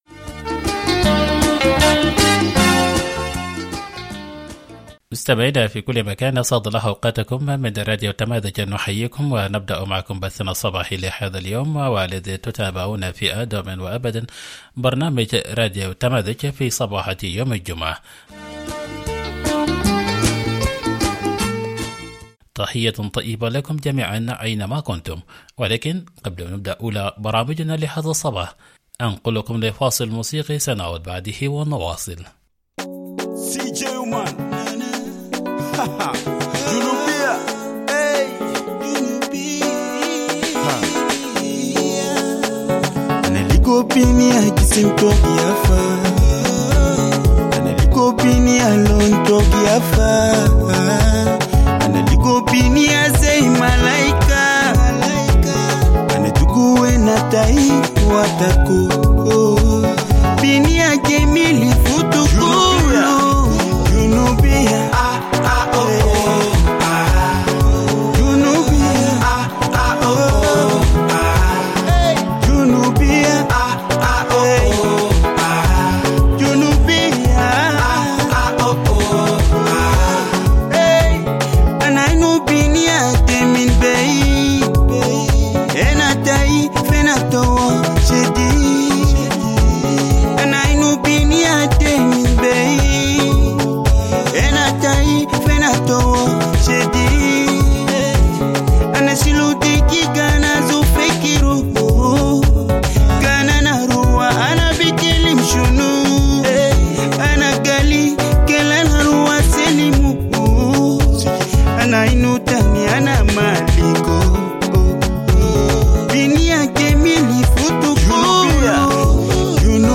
Morning Broadcast 03 June - Radio Tamazuj